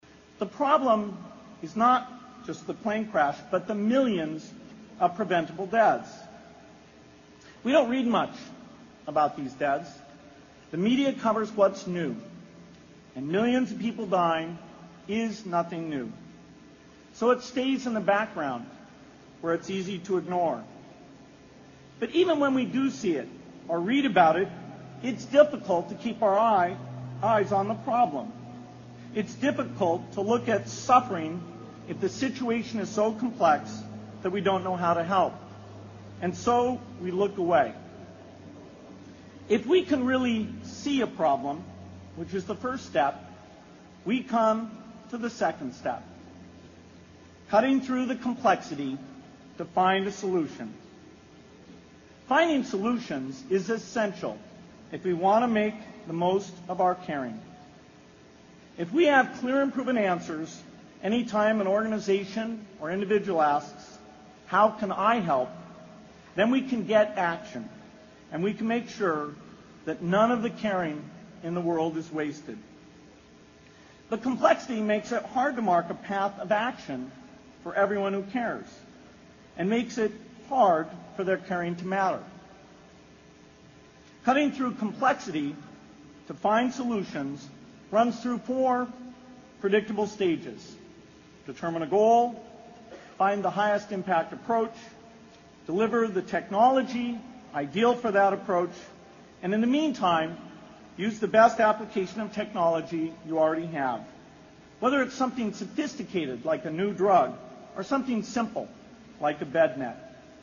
在线英语听力室偶像励志英语演讲 第97期:如何解决这个世界最严重的不平等(7)的听力文件下载,《偶像励志演讲》收录了娱乐圈明星们的励志演讲。